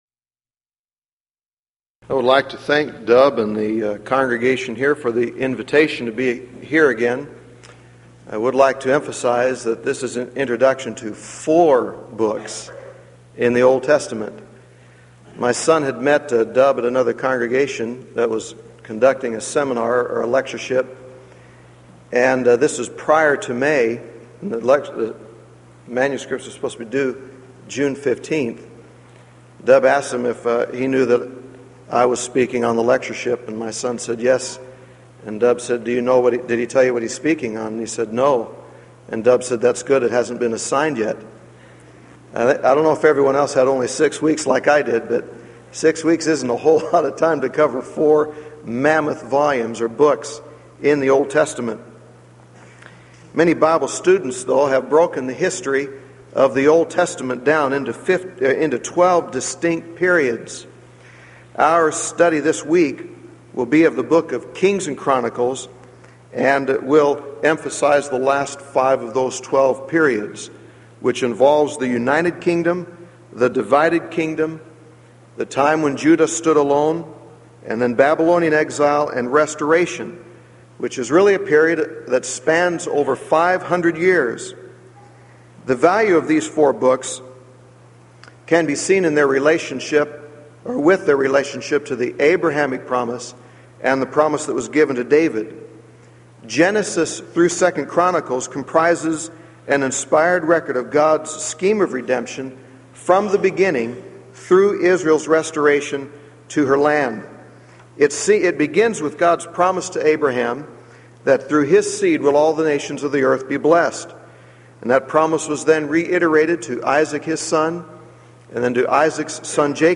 Event: 1993 Denton Lectures
lecture